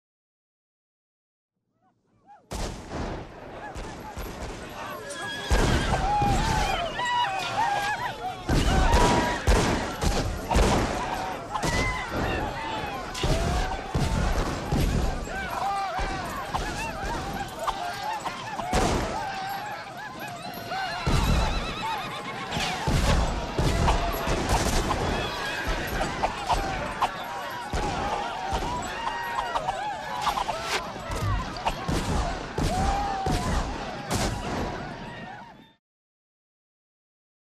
Звуки разбойников
Нападение шайки разбойников